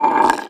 glass_m2.wav